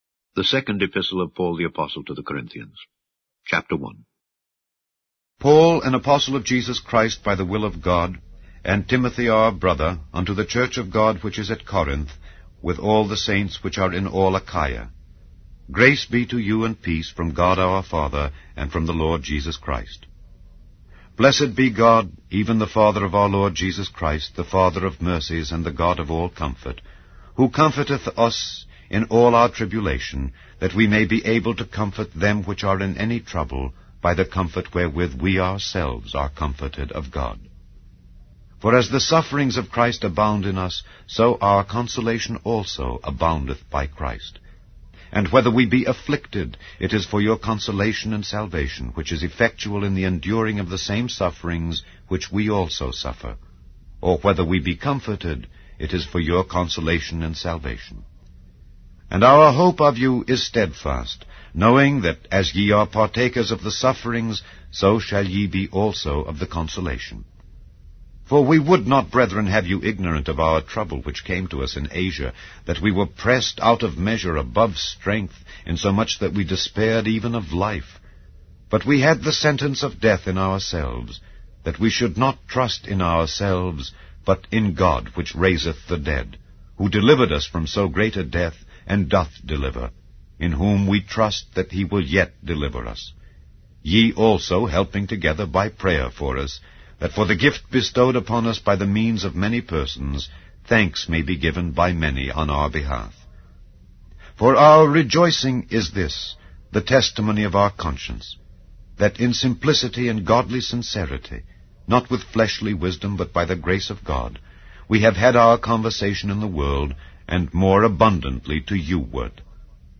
Non-Drama